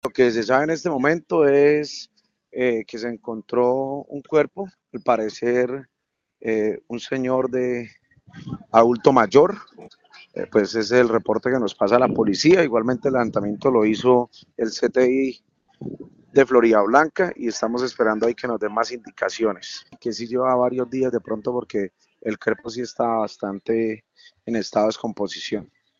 Andrés Ardila, secretario del Interior de Floridablanca